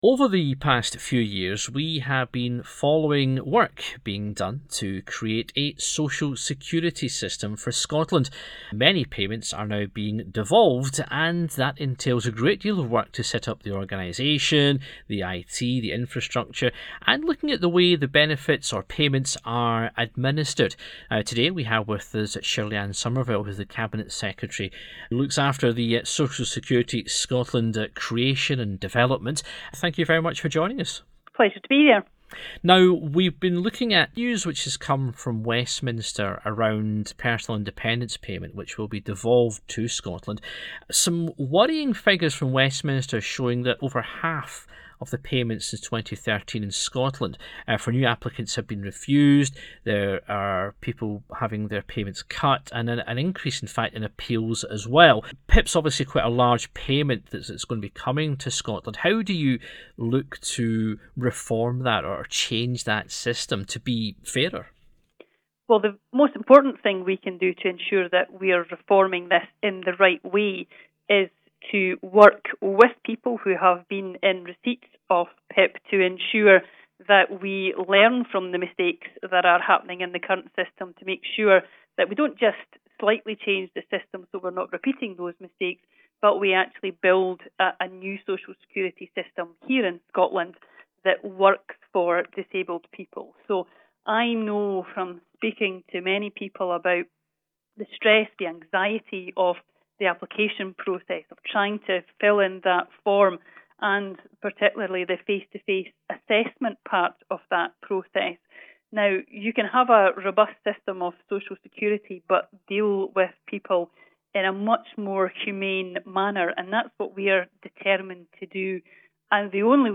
spoke to Cabinet Secretary Shirley-Anne Somerville, who is responsible for the new department